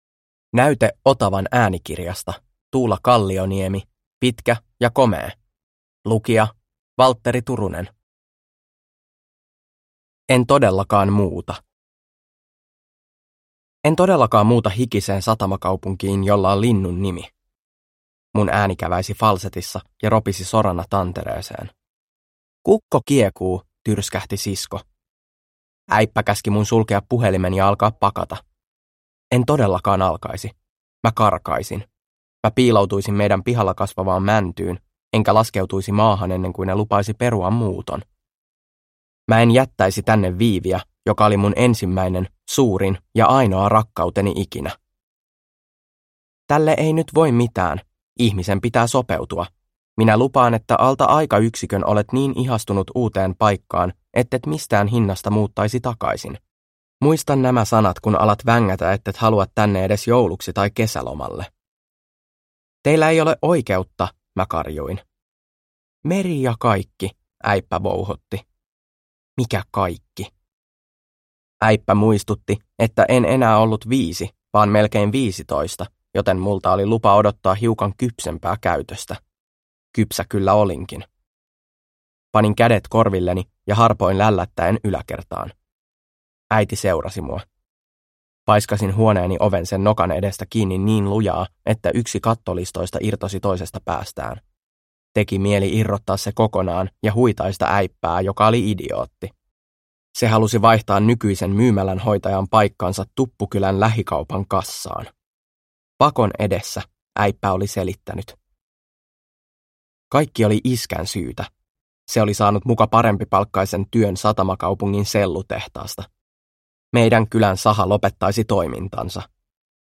Pitkä ja komee – Ljudbok – Laddas ner